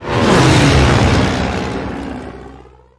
flyby.wav